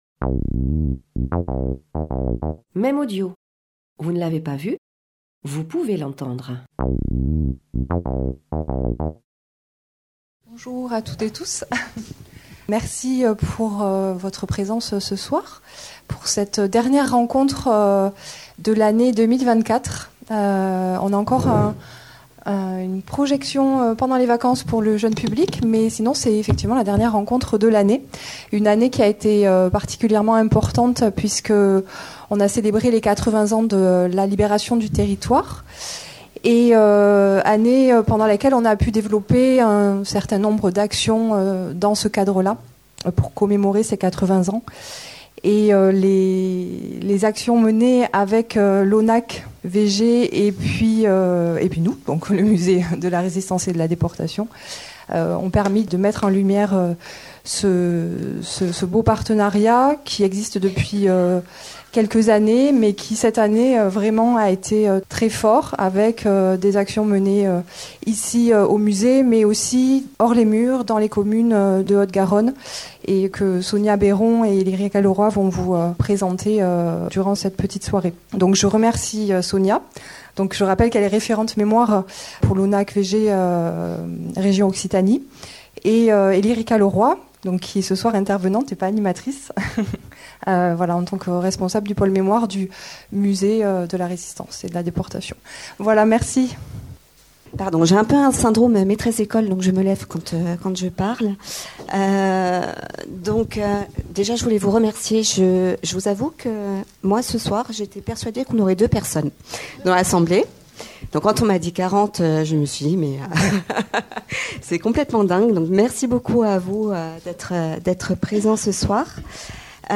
80ème anniversaire de la libération, conférence donnée au Musée Départemental de la Résistance et de la Déportation à Toulouse, le 19/12/2024.
Conférence